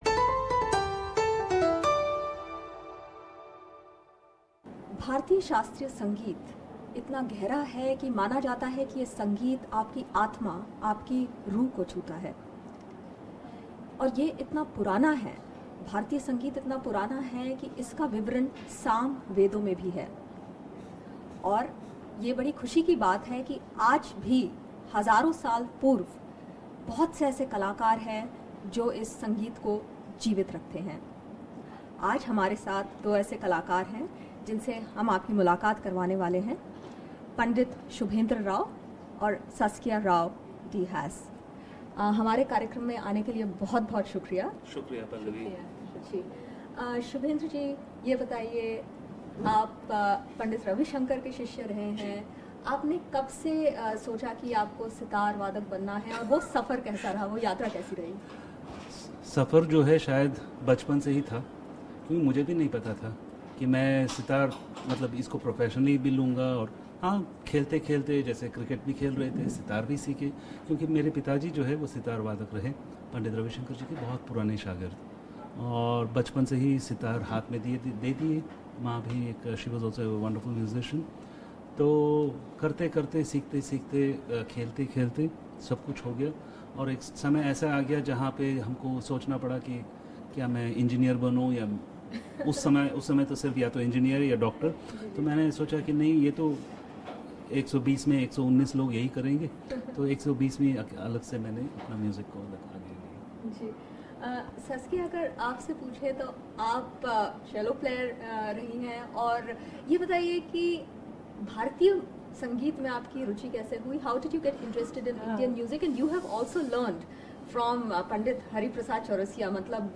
Meet the Globally renowned Sitar player Pandit Shubhendra Rao and Pioneer of the Indian Cello Saskia Rao-de-Haas.
The Globally renowned Indian Classical Musicians talk about all things music with us and also share with us how they became a couple.